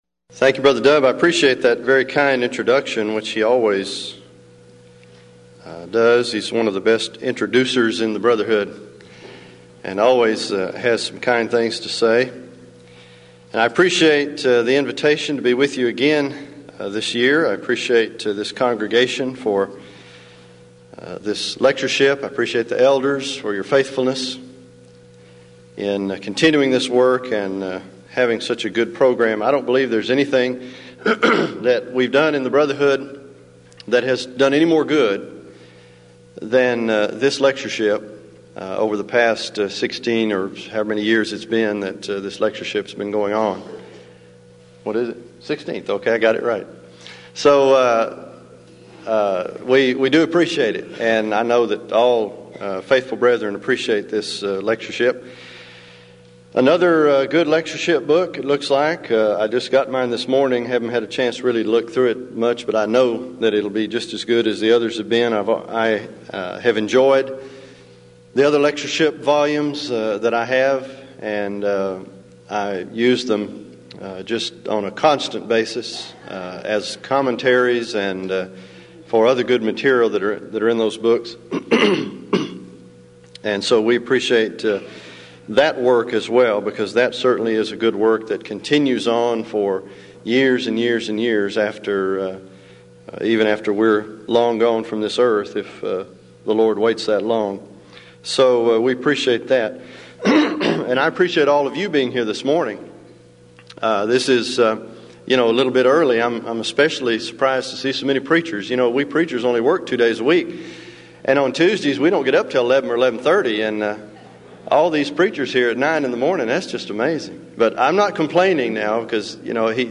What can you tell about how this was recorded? Event: 16th Annual Denton Lectures Theme/Title: Studies In Ephesians